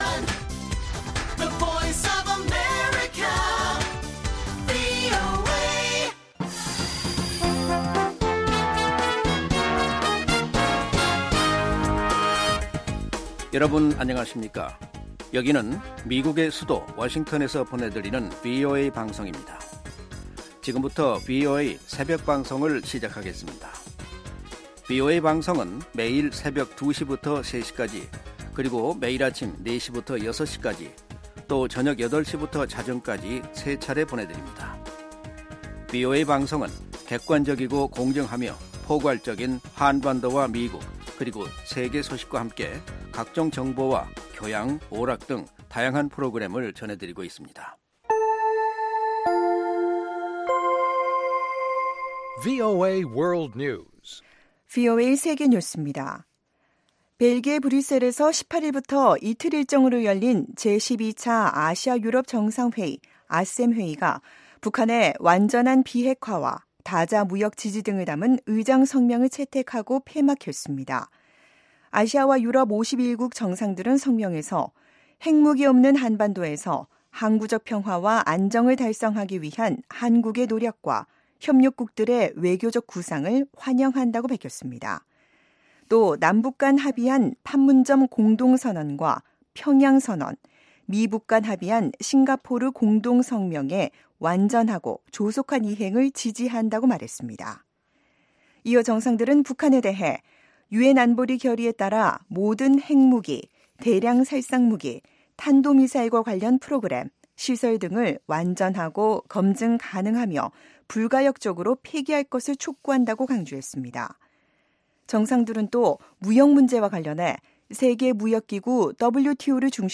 VOA 한국어 '출발 뉴스 쇼', 2018년 10월 20일 방송입니다. 공석이던 국무부 동아태 담당 차관보에 데이비드 스틸웰 예비역 공군 준장이 지명됐습니다.